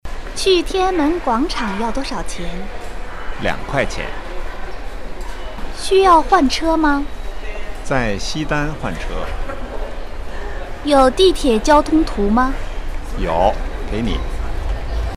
雑音有：